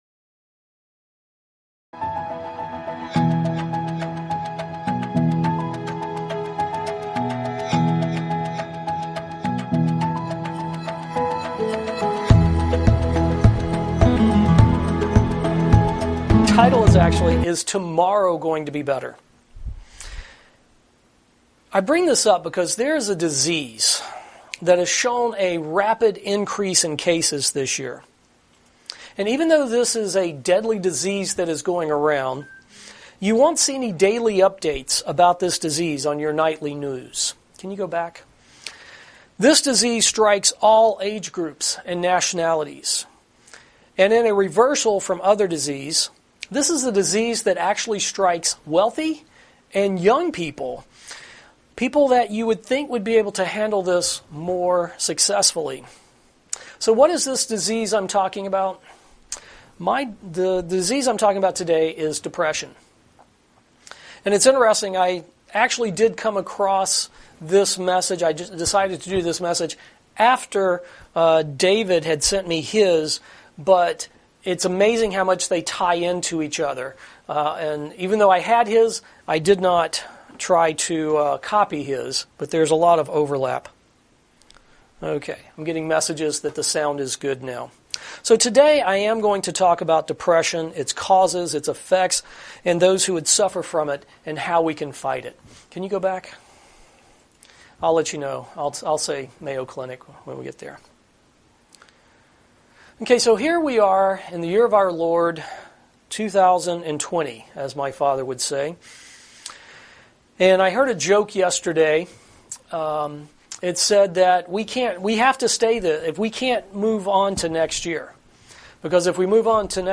Sermons
Given in Ft. Lauderdale, FL